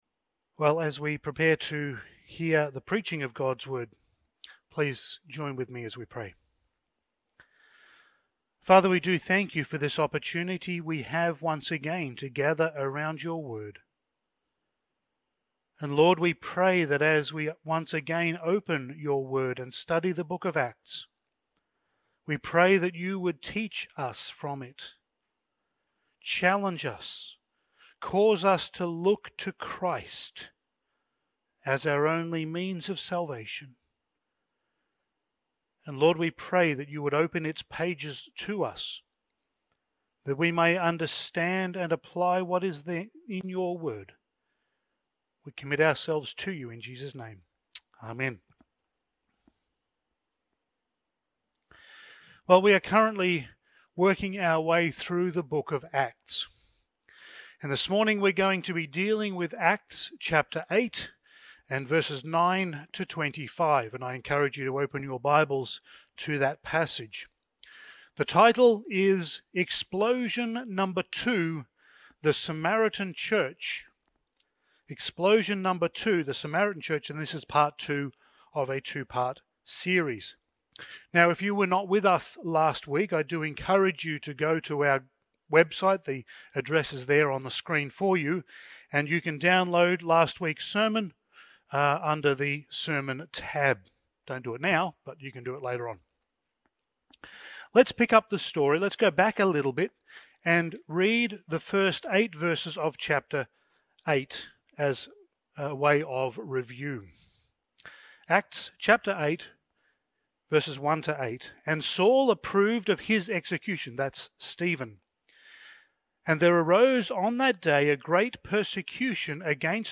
Passage: Acts 8:9-25 Service Type: Sunday Morning